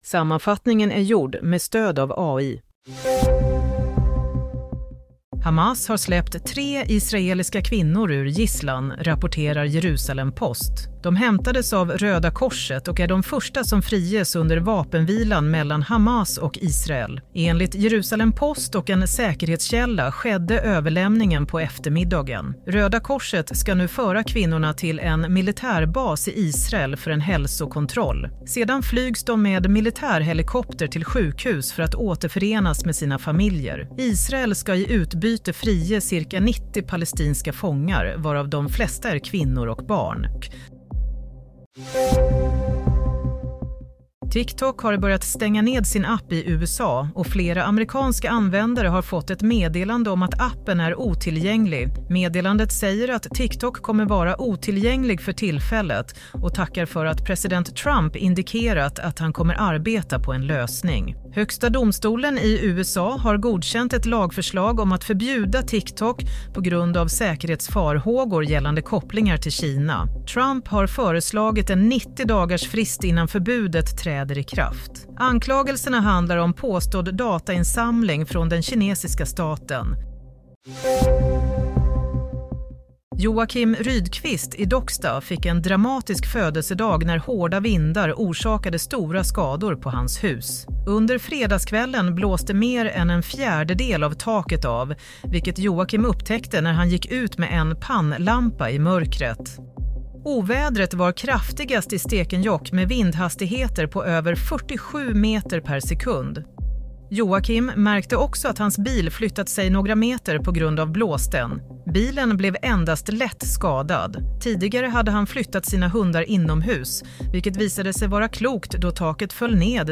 Play - Nyhetssammanfattning - 19 januari 16:00